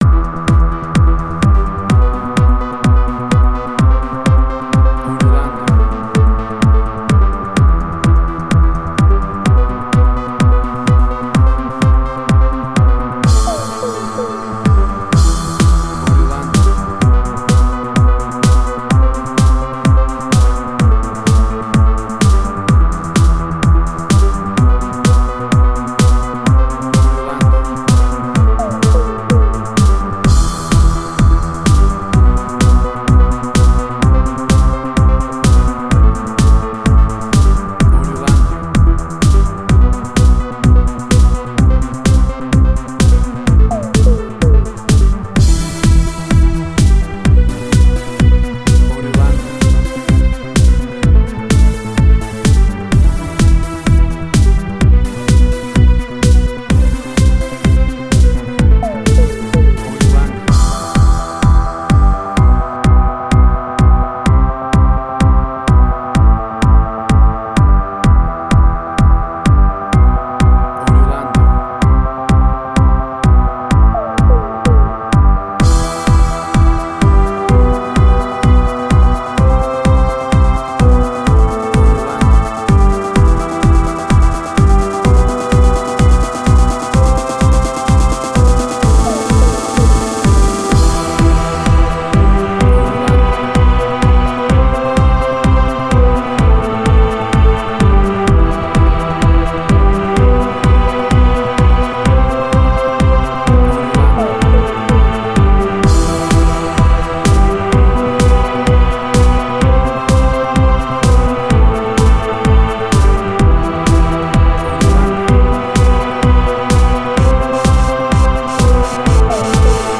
Jewish Techno Trance.
WAV Sample Rate: 16-Bit stereo, 44.1 kHz
Tempo (BPM): 127